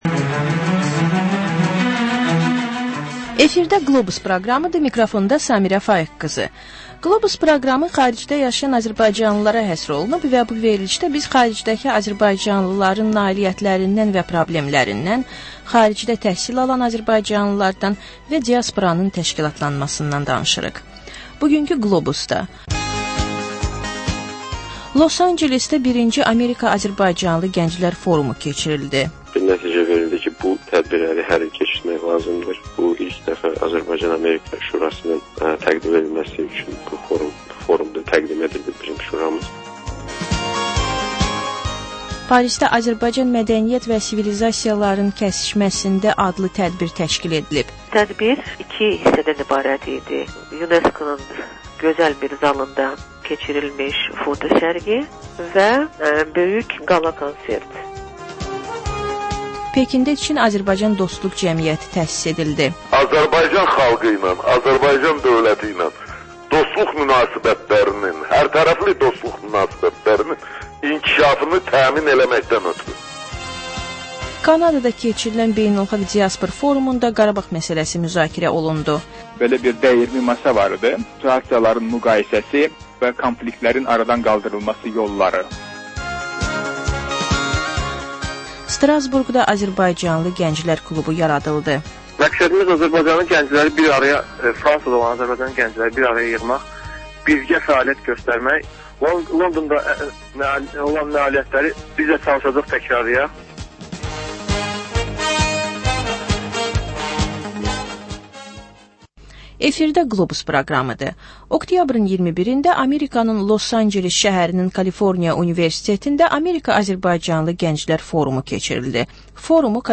Səhər xəbərləri